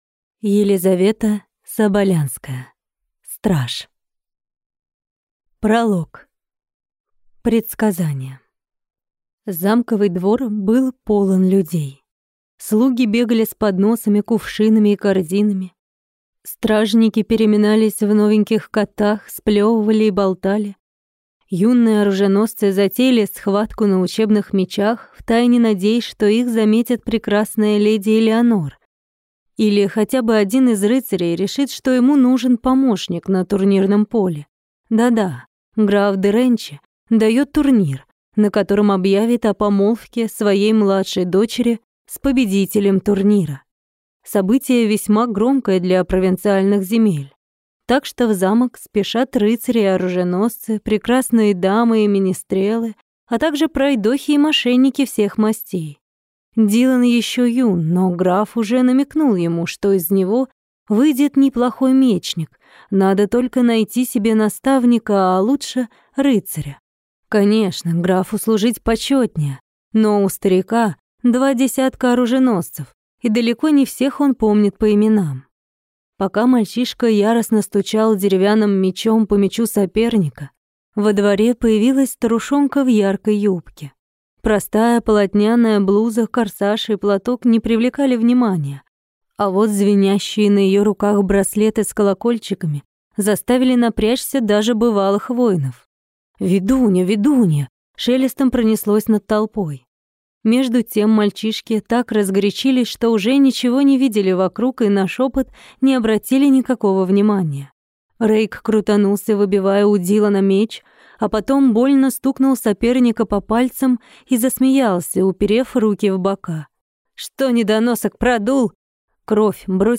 Аудиокнига Страж | Библиотека аудиокниг
Прослушать и бесплатно скачать фрагмент аудиокниги